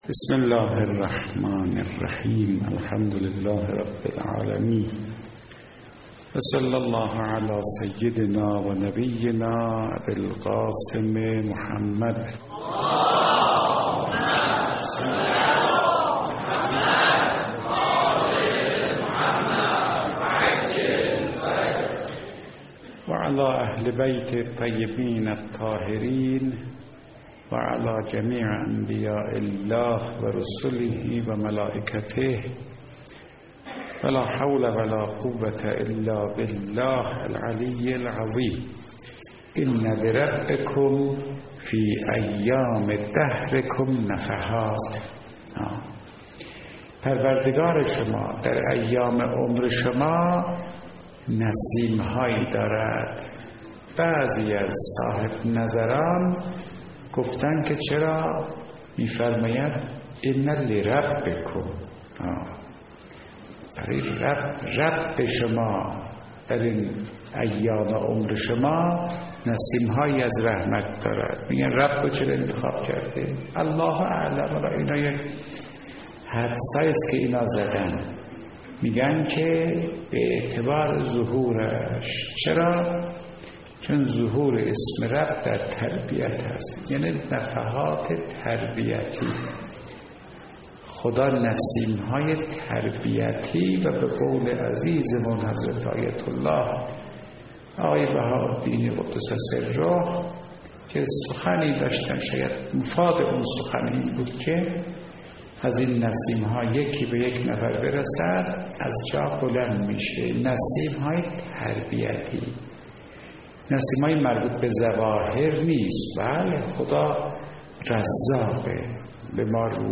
درس اخلاق | معنای بی‌تفاوتی به دنیا در زندگی بزرگان و علما
به گزارش خبرگزاری حوزه، مرحوم آیت الله فاطمی نیا در یکی از سخنرانی های خود به موضوع «نسیم‌های رحمت خدا» پرداخت که تقدیم شما فرهیختگان می شود.